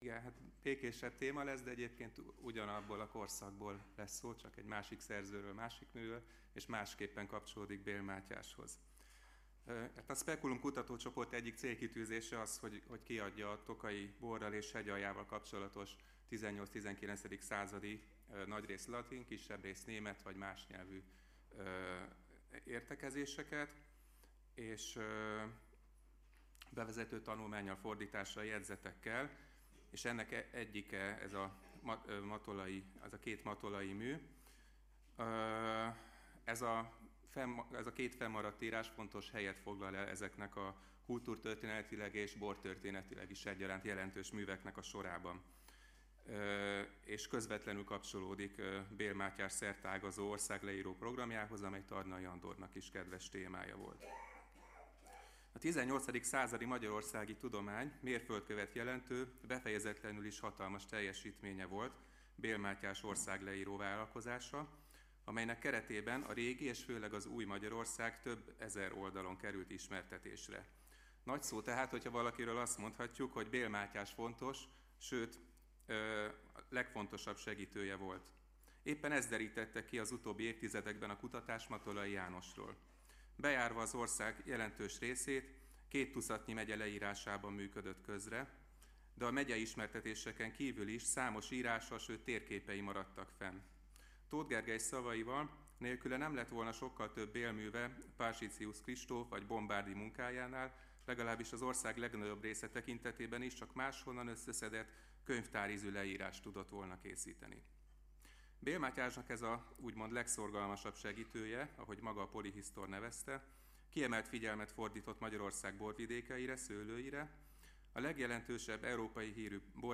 Hagyományőrzés és önbecsülés. Száz éve született Tarnai Andor , Harmadik ülés
(lecturer)